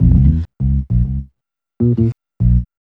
4608L B-LOOP.wav